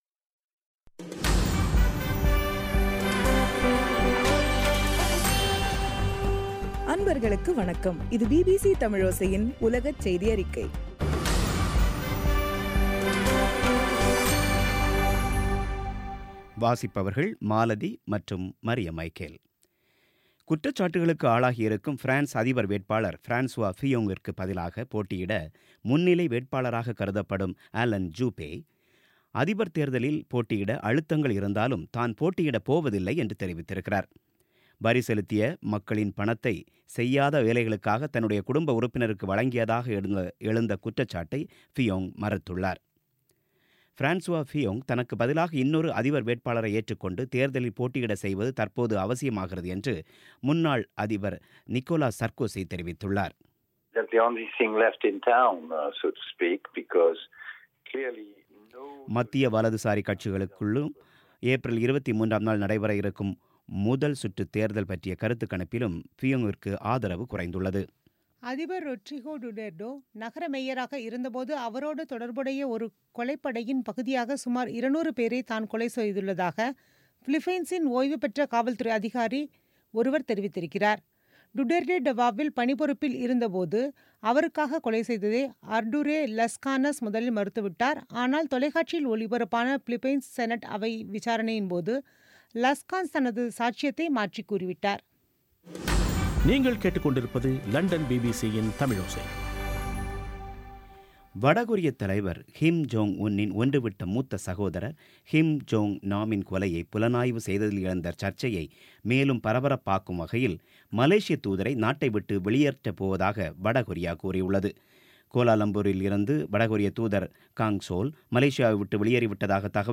பிபிசி தமிழோசை செய்தியறிக்கை (06/03/2017)